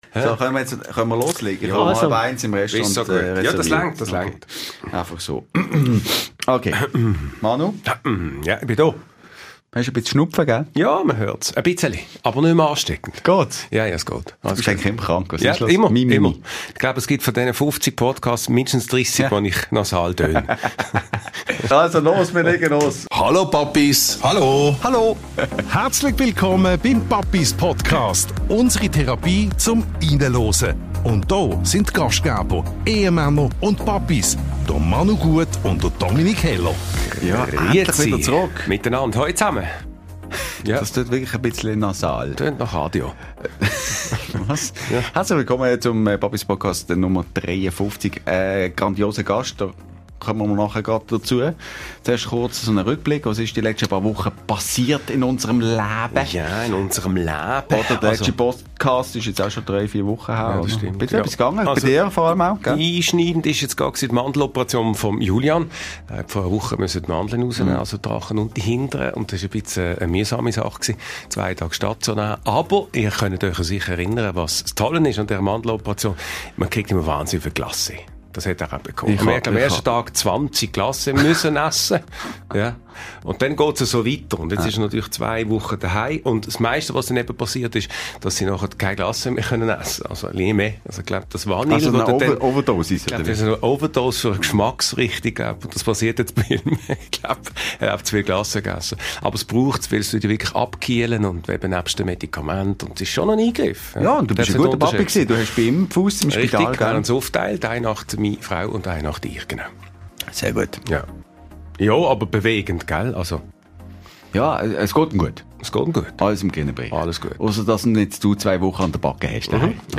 Beschreibung vor 1 Woche Er will nicht als Legende bezeichnet werden – wir machen es trotzdem: Fussballlegende Beni Huggel ist zu Gast in unserer Therapierunde!